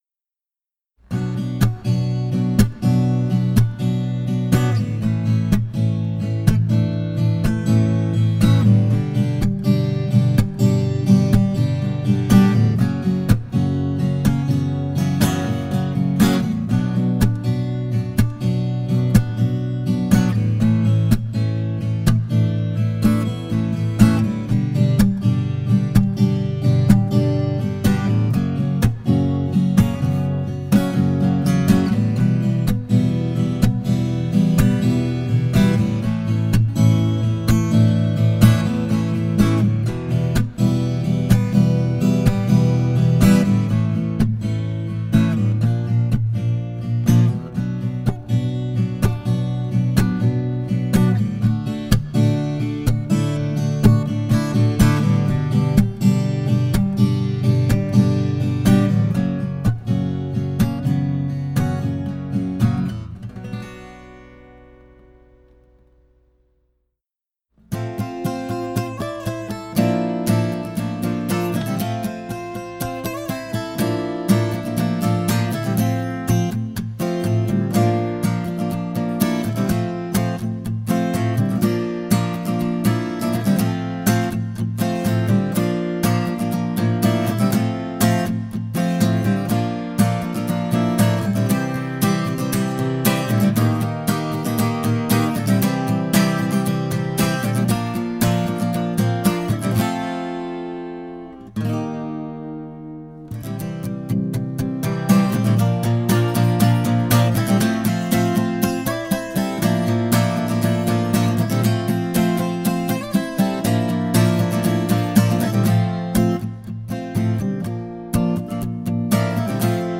More volume, deeper basses: a true modern retro!
The low end is rich and surprisingly full for a smaller-bodied guitar, with a rounded bass that supports rather than overwhelms.Mids are warm, present, and slightly forward, great for vocal accompaniment and fingerstyle nuance. Her high notes  are sweet and clear without being piercing, giving chords a cohesive shimmer and single notes a singing quality.
Also worth noting is that hog guitars offer a dry and focussed tone with a quick fundamental and less overtone wash than spruce-topped Martins — making it great for recording and mixing without much EQ fuss.
Sound-impression-Martin-00-DB-Jeff-Tweedy-1.mp3